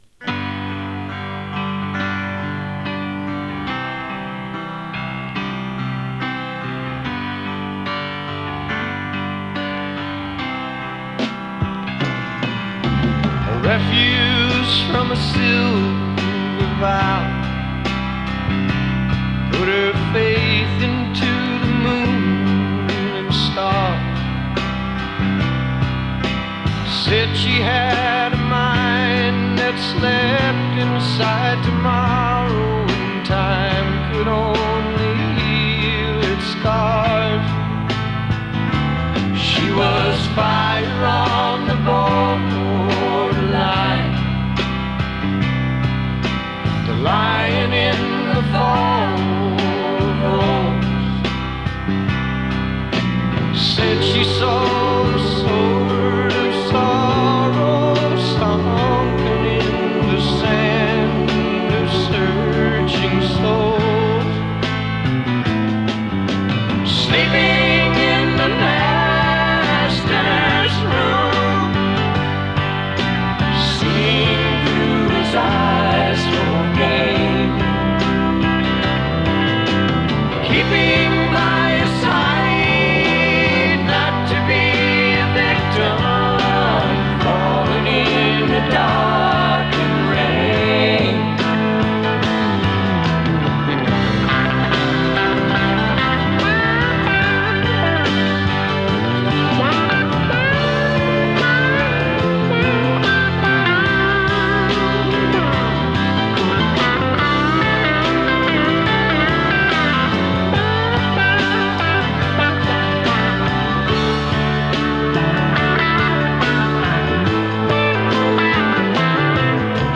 アシッド カントリー フォーク ファンク